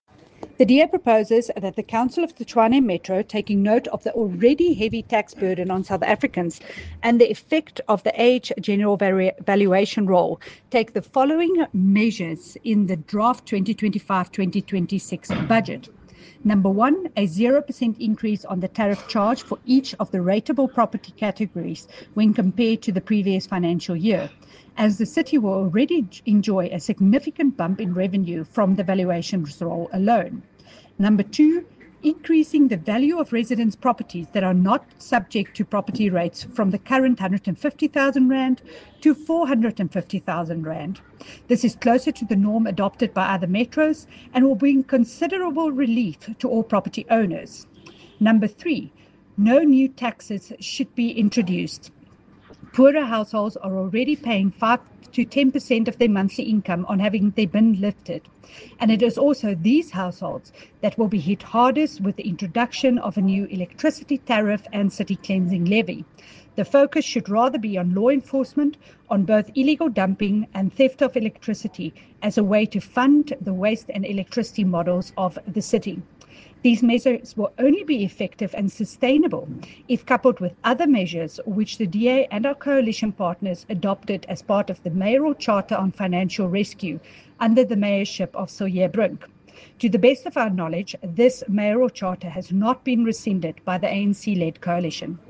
Note to Editors: Please find an English soundbite by Cllr Jacqui Uys